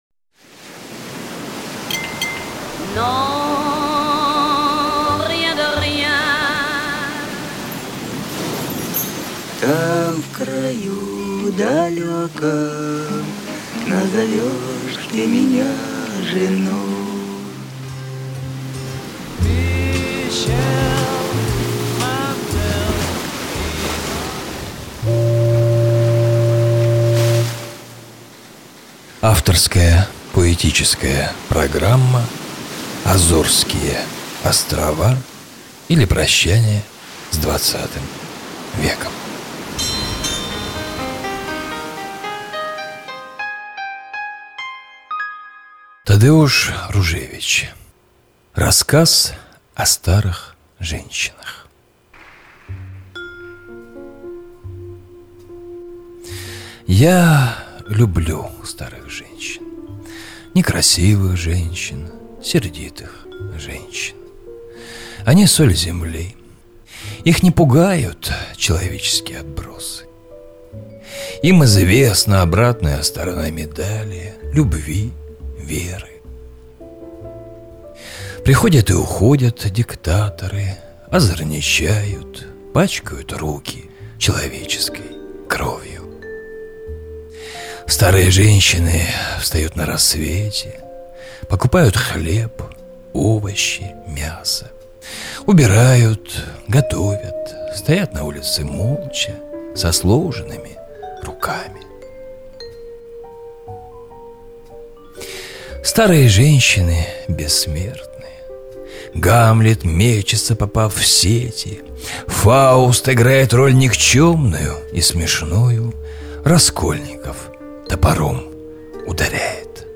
На протяжении достаточно долгого времени, начиная с 1999 года, на разных радиостанциях города* выходили мои авторские поэтические и литературно-музыкальные программы – «АЗОРСКИЕ ОСТРОВА, ИЛИ ПРОЩАНИЕ С ХХ ВЕКОМ…», «ЖАЖДА НАД РУЧЬЁМ», «НА СОН ГРЯДУЩИЙ», «ПолУночный КОВБОЙ», «ПОСЛУШАЙТЕ!».
Музыка –А.Кондакова, G.Delerue